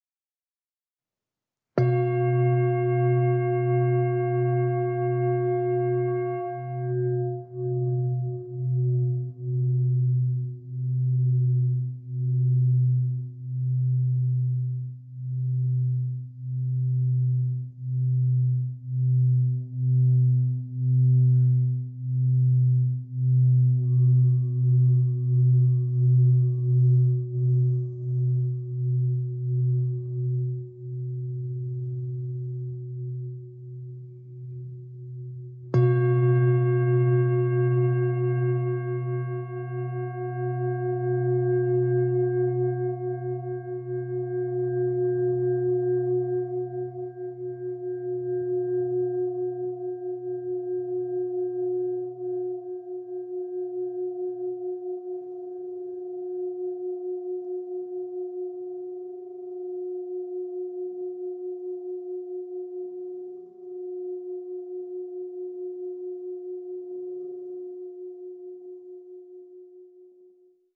MEINL SONIC ENERGY SINGING BOWL 1000G - UNIVERSAL SERIES
The Meinl Sonic Energy Singing Bowl, 1000g, delivers a clear, rich sound while remaining affordable.
When lightly tapped, the bowl produces a deep fundamental tone. At the same time, layered overtones develop and expand into the surrounding space. As the sound resonates, it creates a calm and immersive atmosphere. Furthermore, the vibration continues long after the initial strike. Because of this sustained resonance, listeners often experience a deep sense of relaxation.
• Material: Special bronze alloy